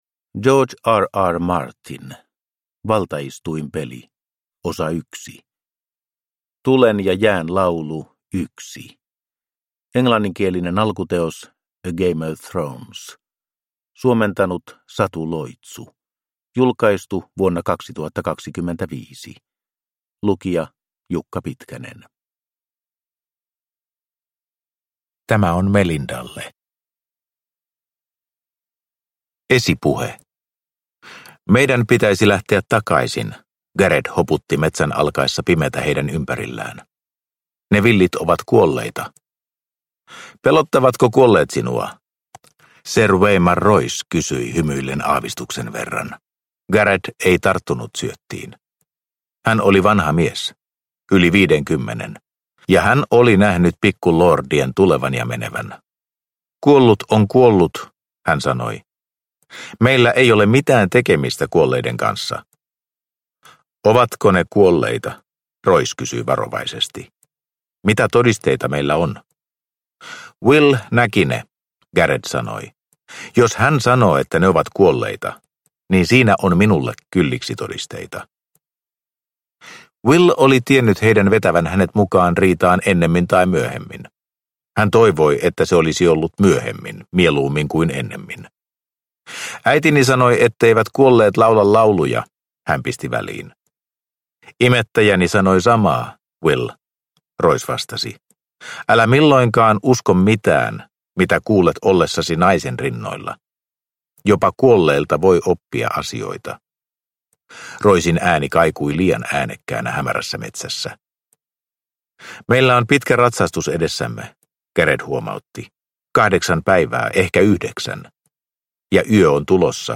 Valtaistuinpeli 1 – Ljudbok
Äänikirjan ensimmäinen osa.